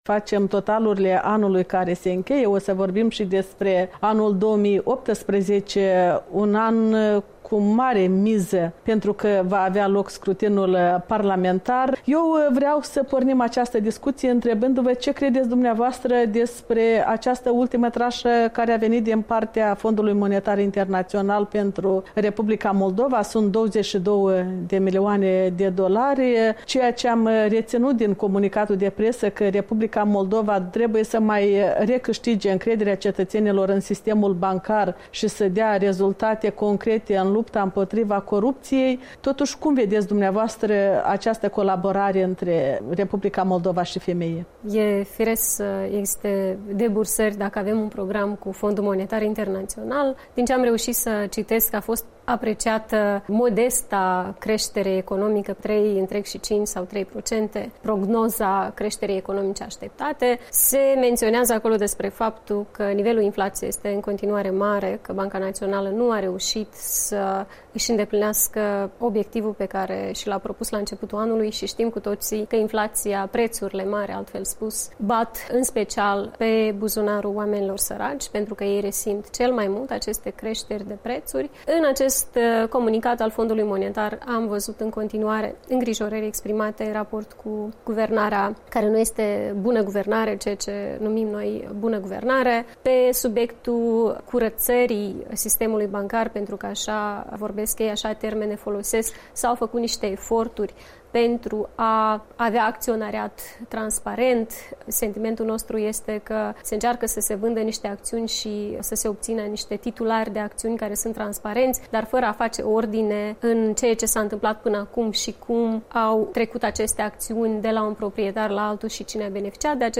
Interviu cu Maia Sandu